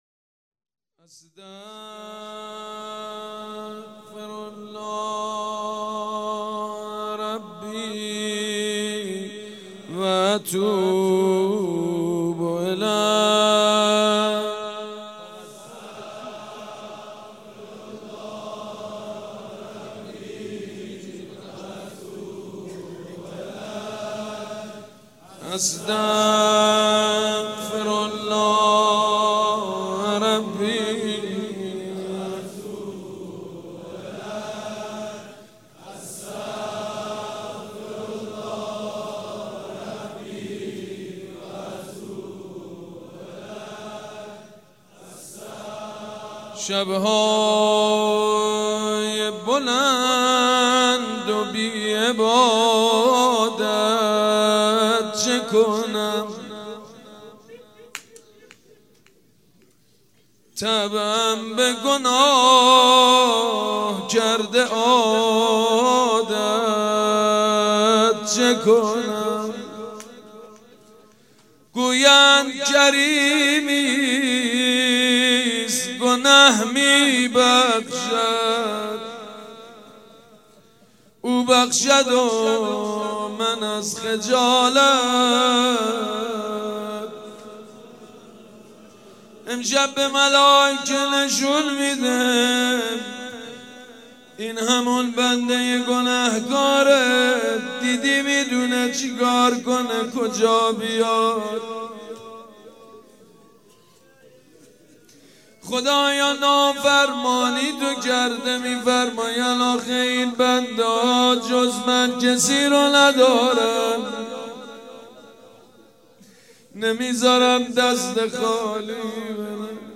مراسم احیا